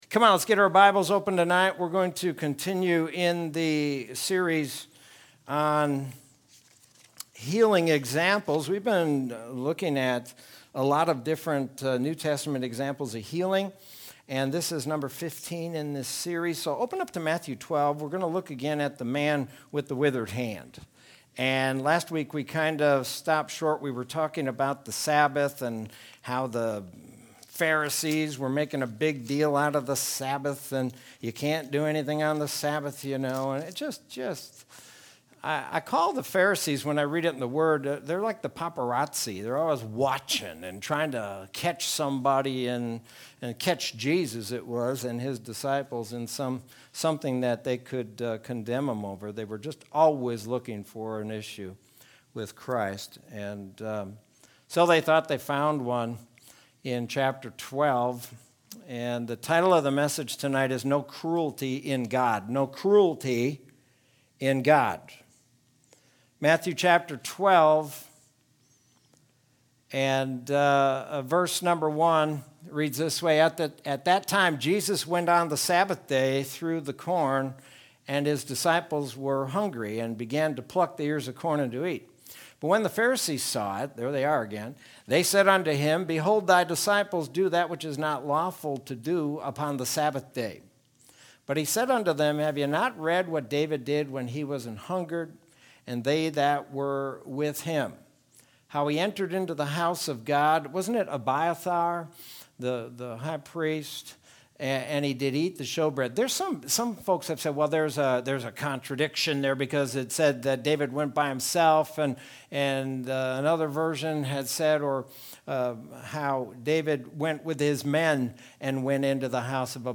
Sermon from Wednesday, May 12th, 2021.